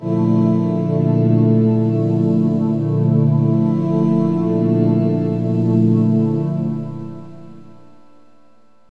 SFX低沉大气中场音乐特效音效下载
SFX音效